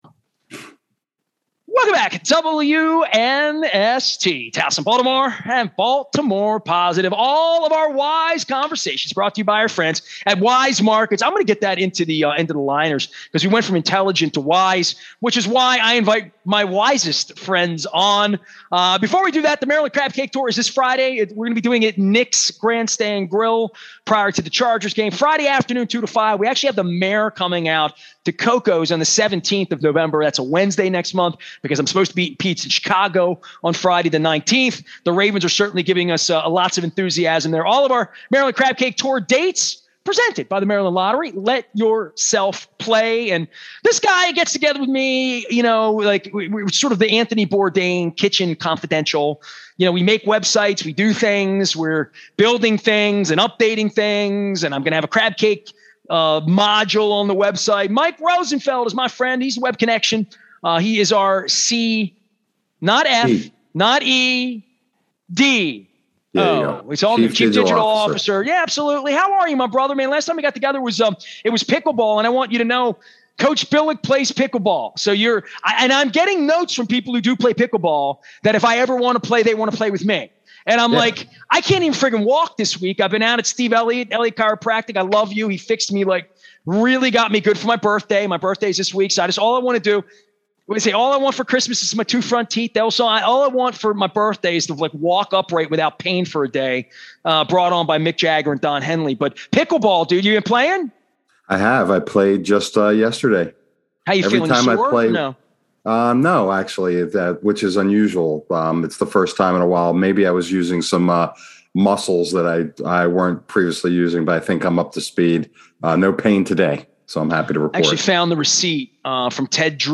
a spirited debate regarding the evils of Facebook and the interweb